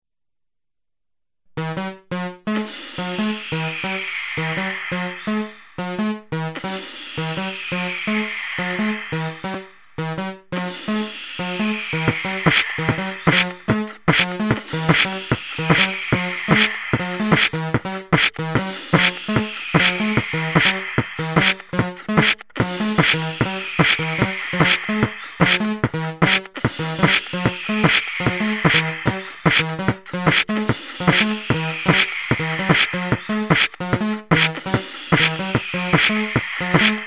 Форум российского битбокс портала » Информационный уголок » Программы и сэмплы » Piano FX Studio (Клавиатурный синтезатор)
Ну и естественно баянный образец состряпаный за пять минут